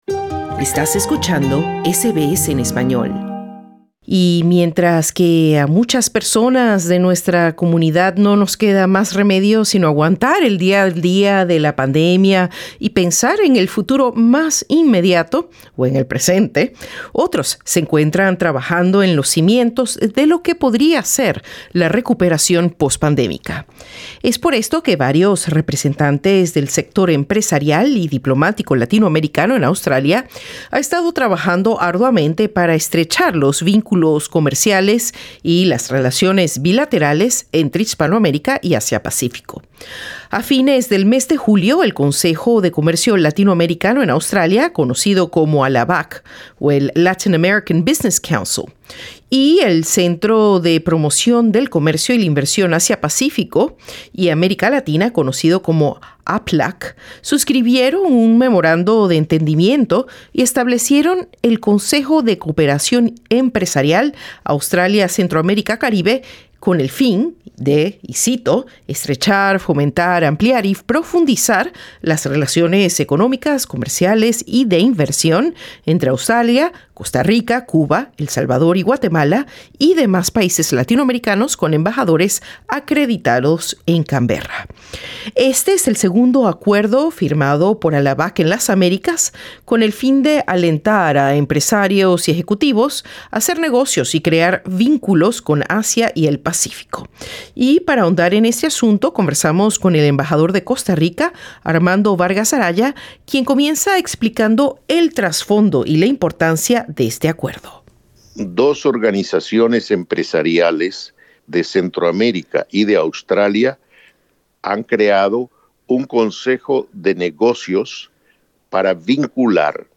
El embajador de Costa Rica, Armando Vargas Araya, conversó con SBS Spanish sobre este esfuerzo diplomático que contempla impulsar sectores clave como energías renovables, el turismo, la minería, la agricultura, la ciencia y la tecnología.